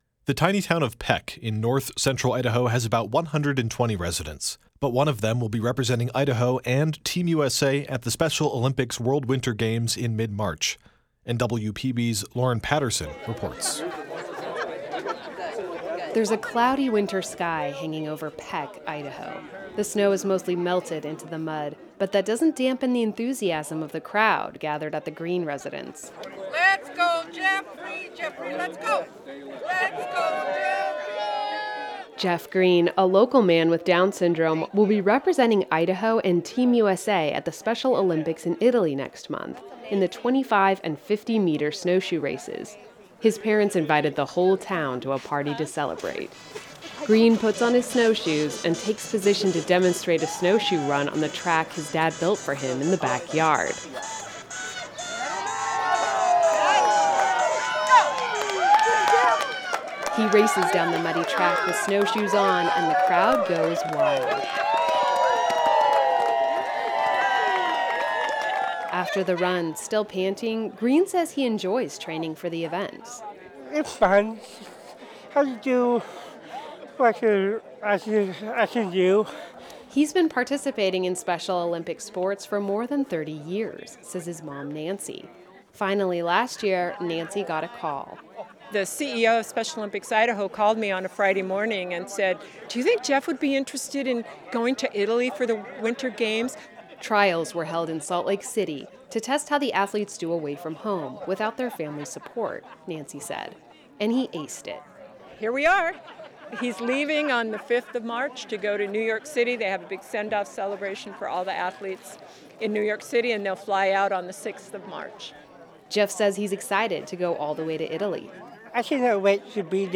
People cheered as he raced down the muddy track.